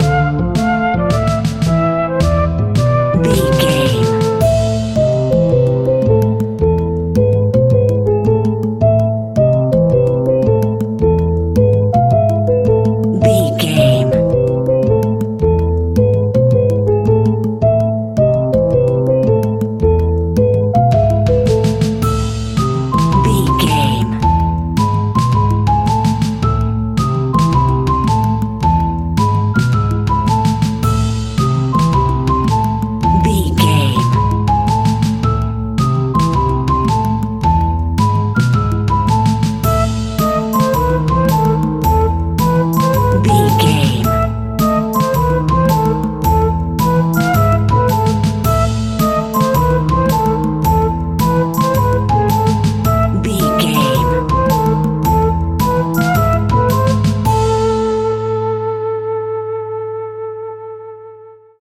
Aeolian/Minor
ominous
haunting
eerie
playful
double bass
piano
drums
brass
spooky
horror music